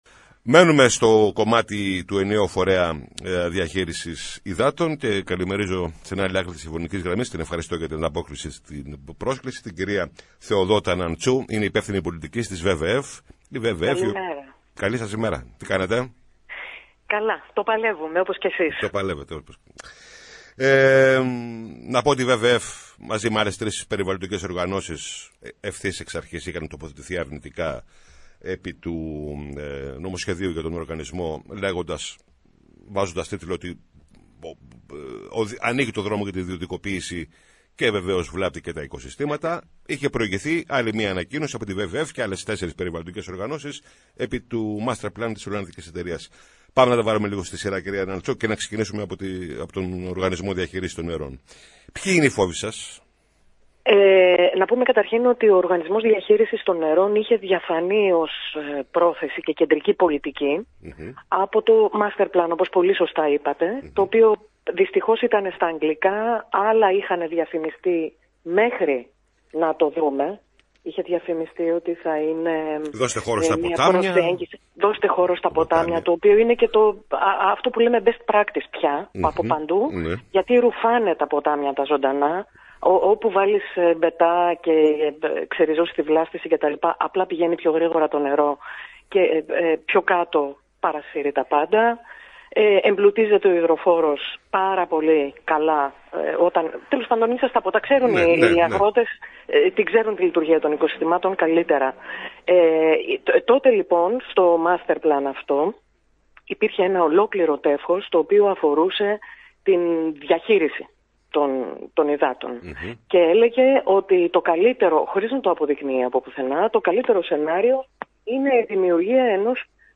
μιλώντας στην ΕΡΤ Λάρισα